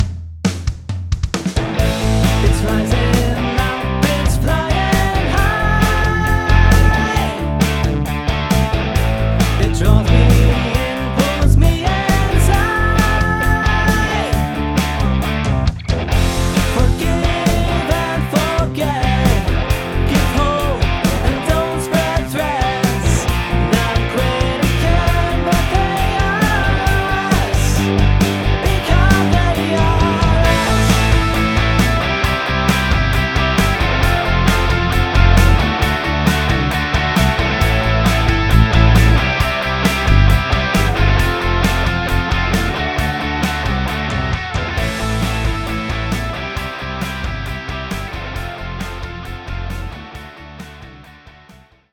So, hier mal mein wiederhergestelltes Layout mit ein bisschen Inhalt. Da gibt's noch relativ viel Arbeit, als da wären: - Drums, Bass und Gitarren besser aufeinander abstimmen (ist eben ein Layout).
Ansonsten finde ich die Idee nach wie vor gut und bin auf meinen kleinen Trick mit den verschobenen Gesangslinien am Ende sogar ein bisschen stolz.